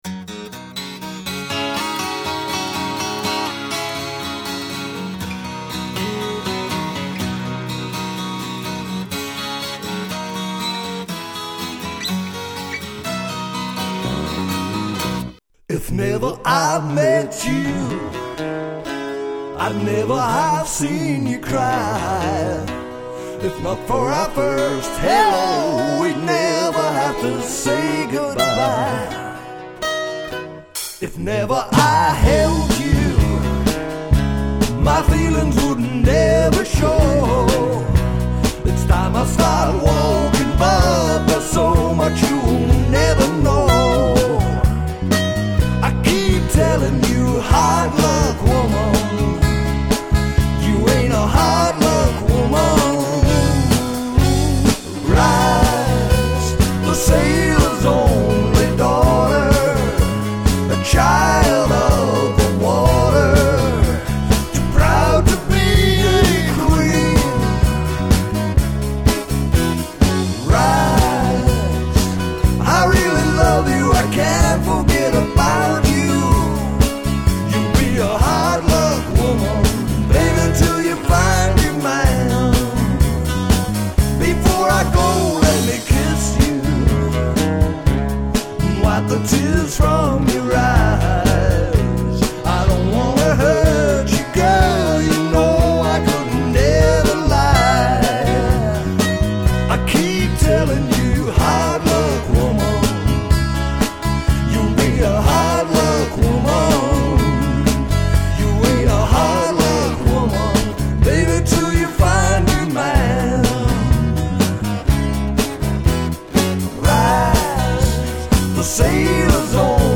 cover, on which I sing both lead vocals